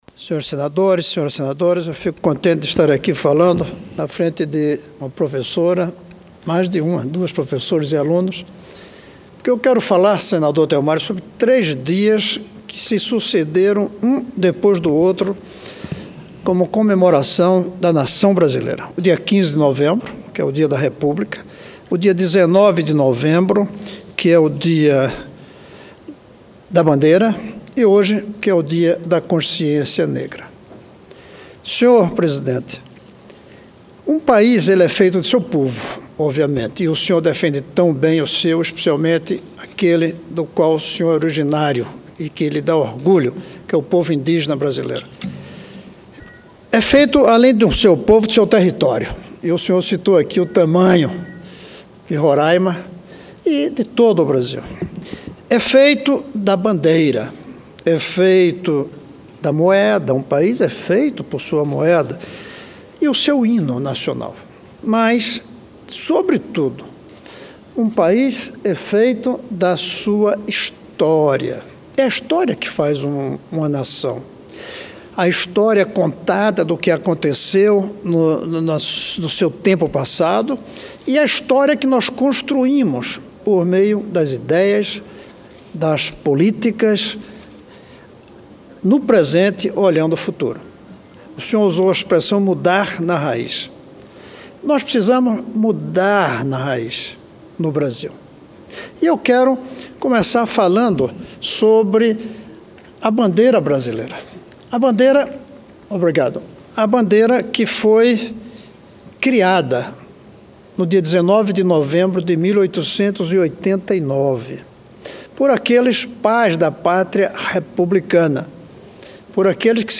Plenário 2015
Discursos